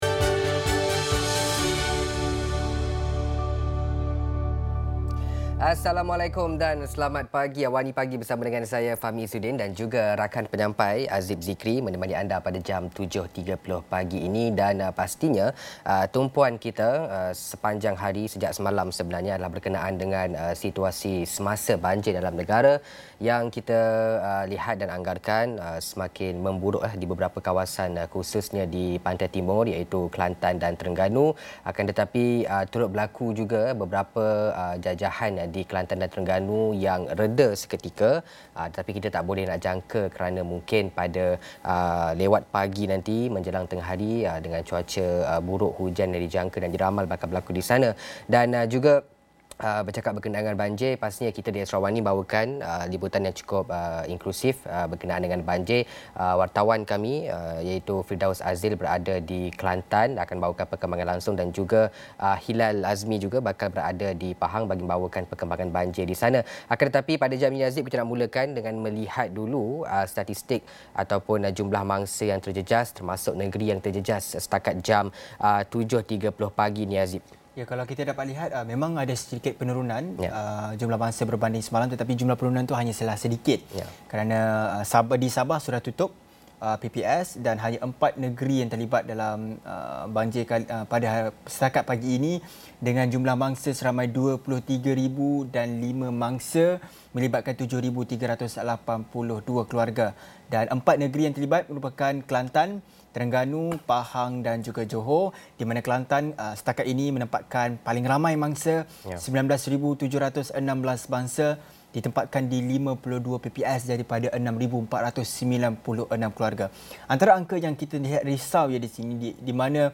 dua mangsa banjir masing-masing dari Tumpat dan Kuala Krai, Kelantan